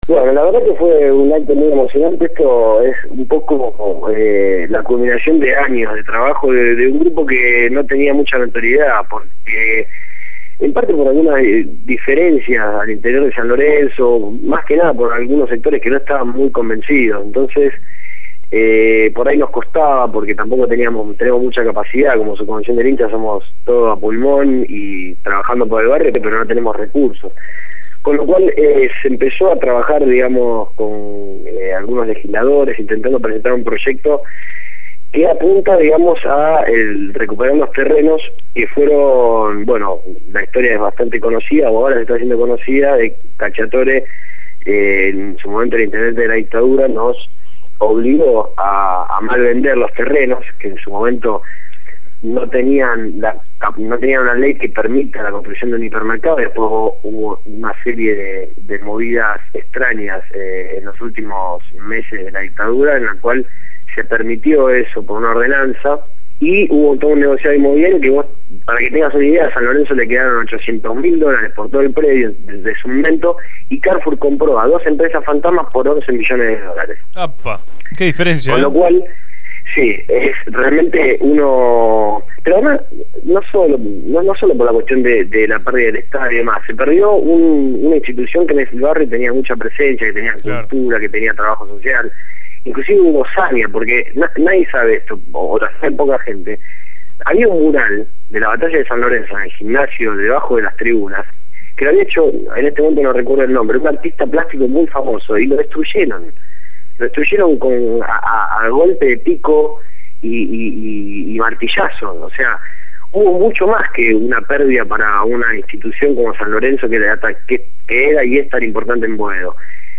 dialogó con el programa «Desde el barrio» (lunes a viernes de 9 a 12 horas) por Radio Gráfica FM 89.3